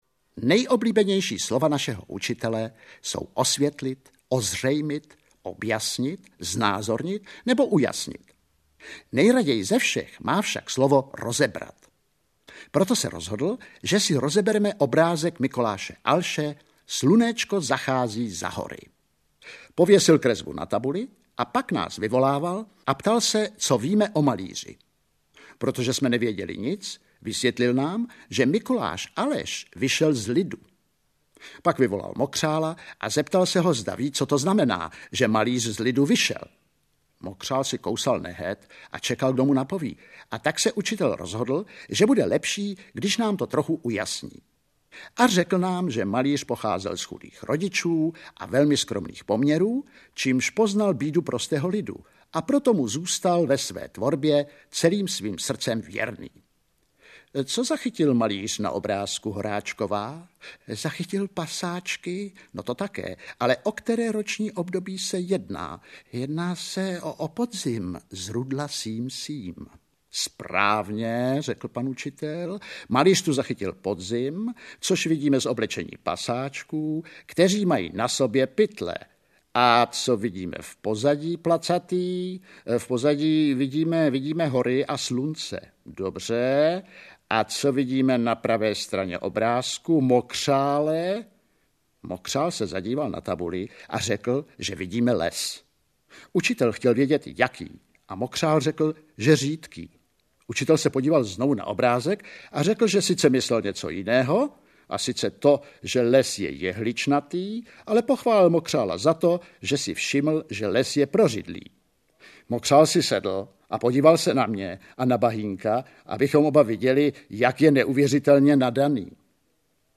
Medová léta audiokniha
Ukázka z knihy